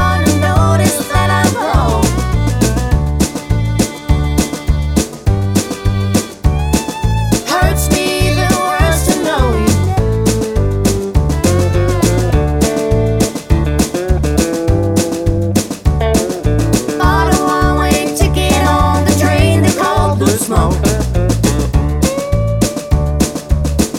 no Backing Vocals Country (Female) 3:31 Buy £1.50